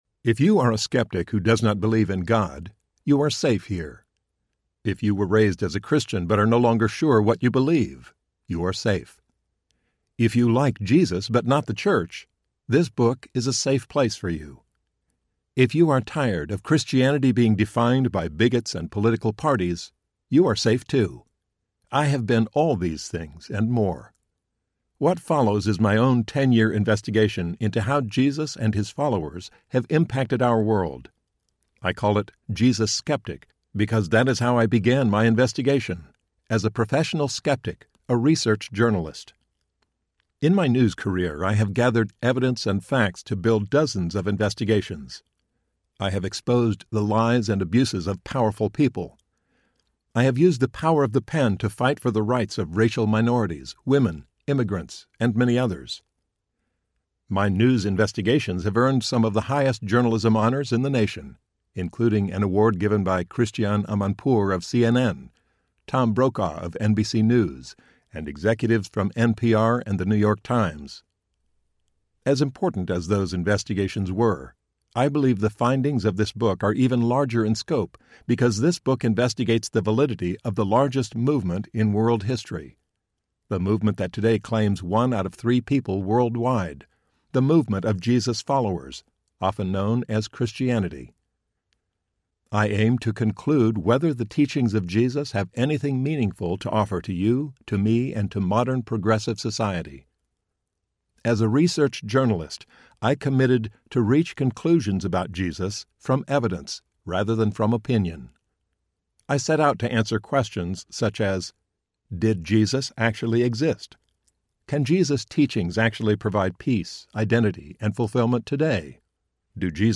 Jesus Skeptic Audiobook
Narrator
6.5 Hrs. – Unabridged